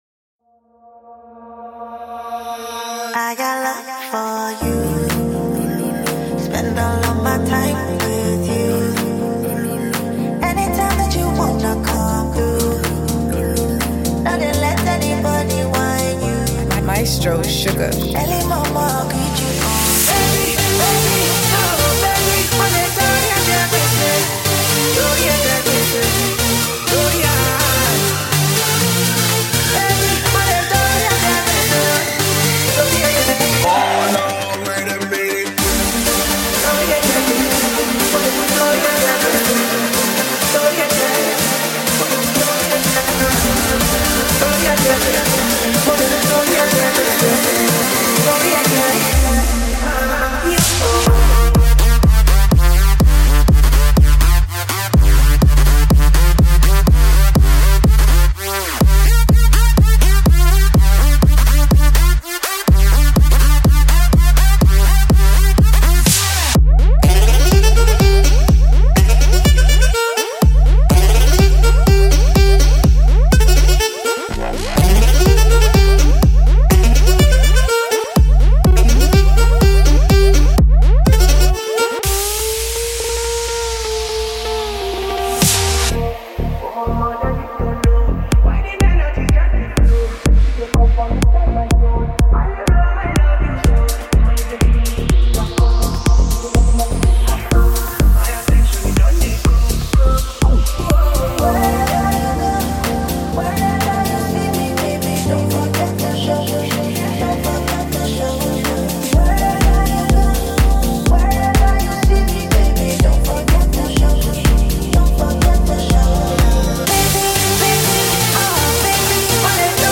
Nigerian singer and songwriter